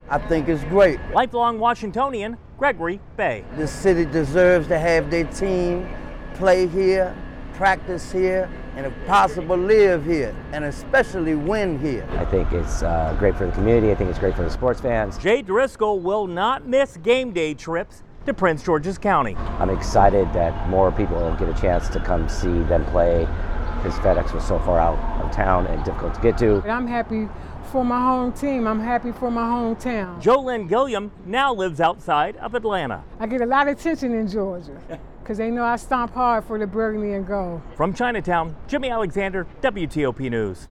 gets reaction from Washington Commanders fans after the announcement of a deal to bring the team back to D.C. and the old RFK Stadium site.